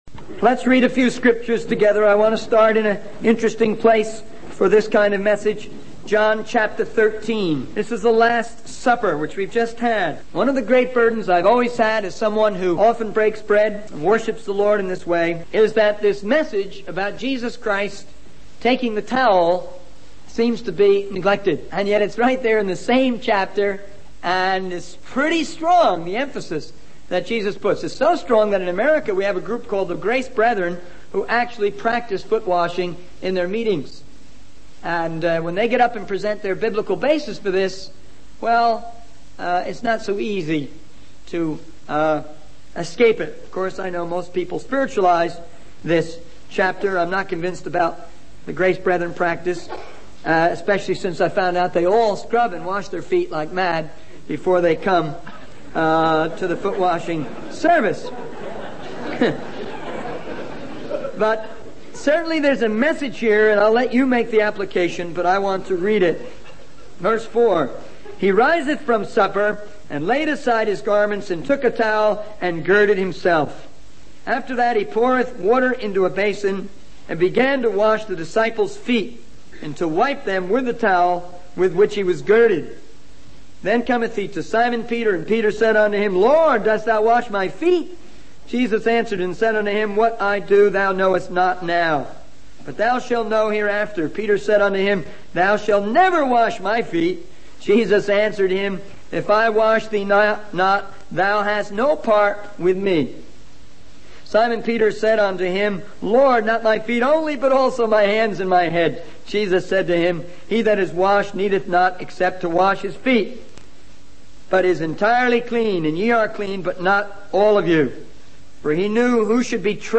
In this sermon, the speaker addresses the issue of people becoming slaves to the pleasure cult, particularly in our country. He emphasizes the importance of not getting caught up in worldly pleasures and instead focusing on the message and challenge of the gospel.